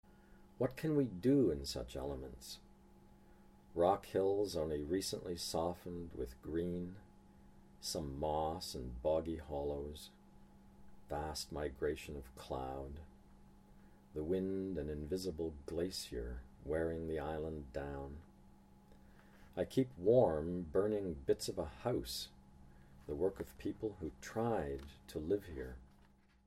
John Steffler reads [what can we do in such elements] from The Grey Islands